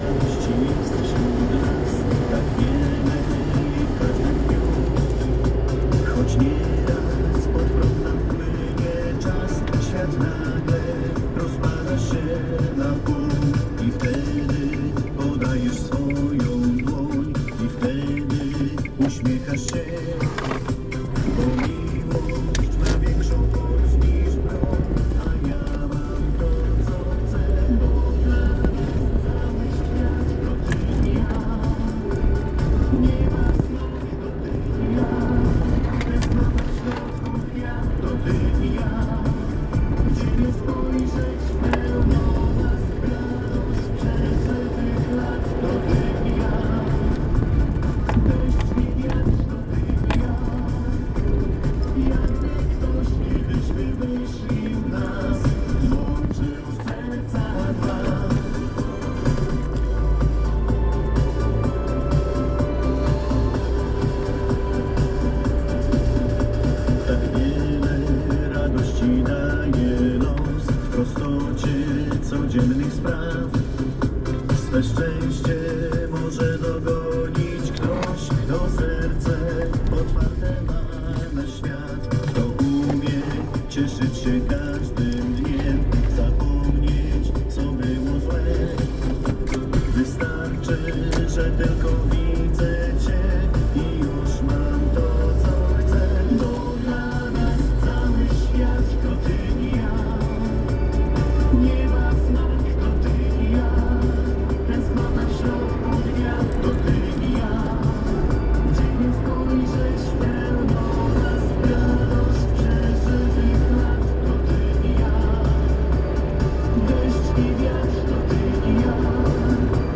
piosenka disco polo